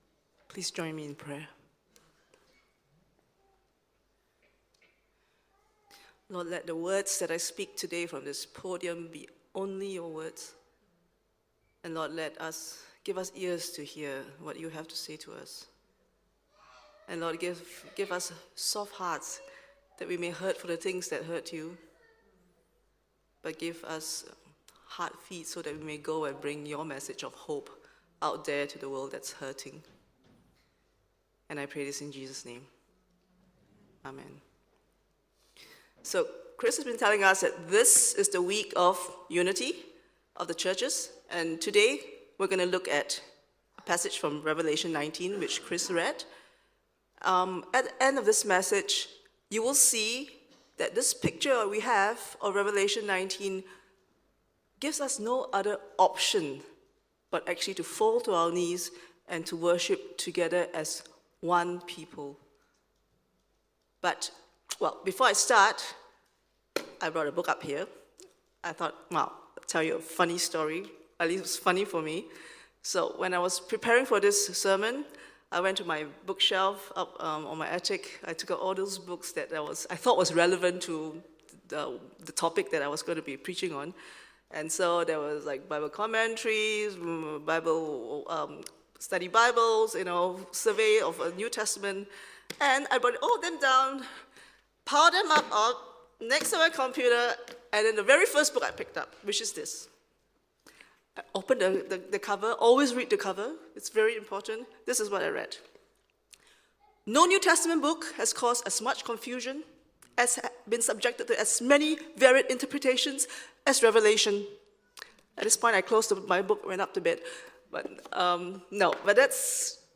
1 Sermon – February 9 , 2025 9:28 Play Pause 1d ago 9:28 Play Pause Nghe Sau Nghe Sau Danh sách Thích Đã thích 9:28 Blessedness!